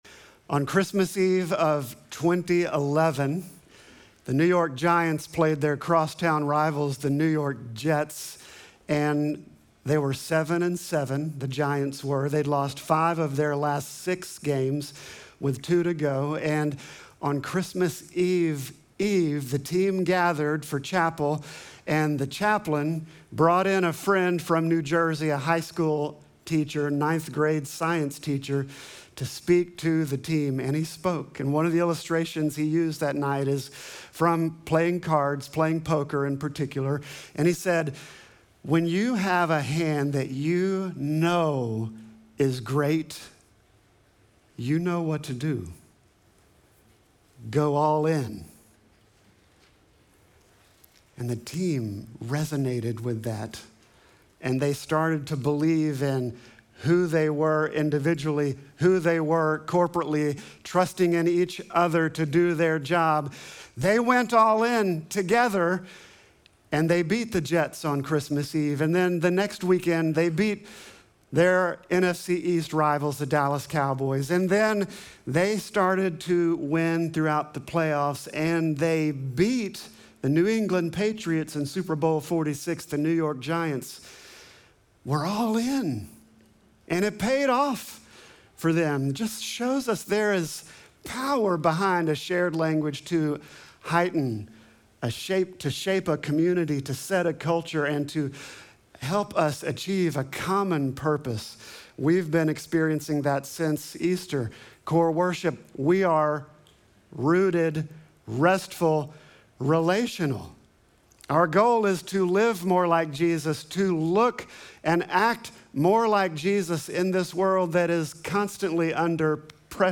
Sermon text: Luke 7:34